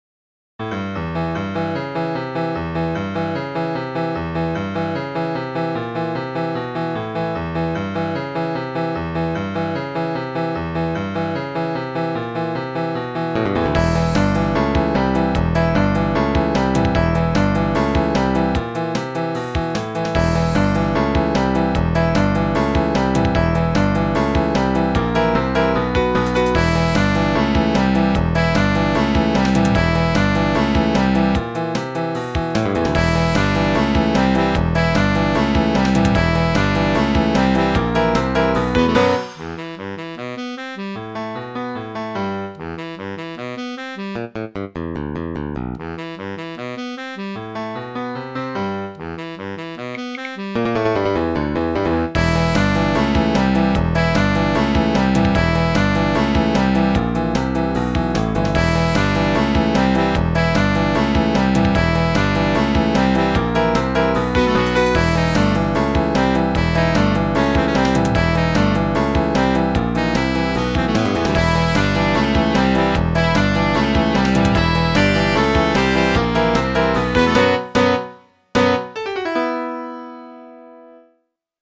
This sounds like it could be a theme for an animated show.
ROCK MUSIC